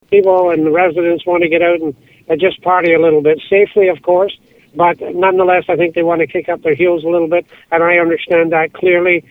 A significant amount of planning has already gone into the 25th anniversary celebrations for the Town of Petawawa. Petawawa Mayor Bob Sweet tells myFM he is looking forward to the event this summer.